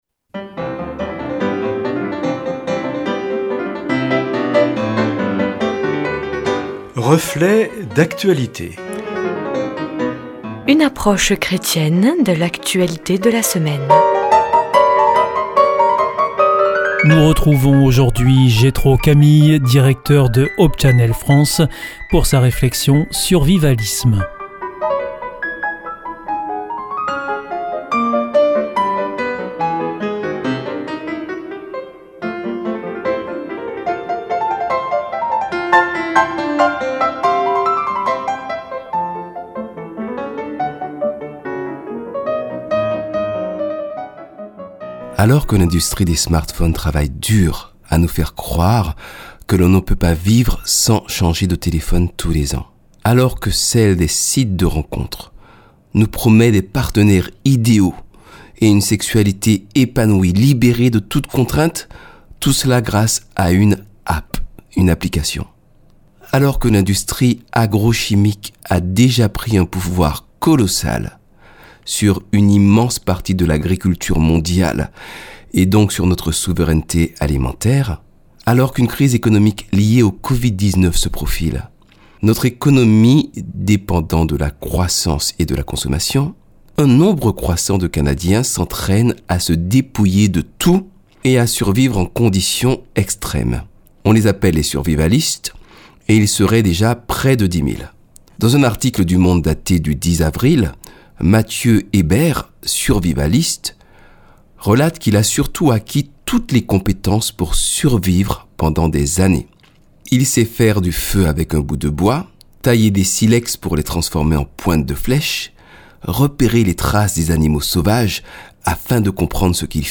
Chronique hebdomadaire produite par la Coordination des radios locales de France avec le concours des professeurs de la Faculté adventiste de théologie, de pasteurs et de chroniqueurs bibliques.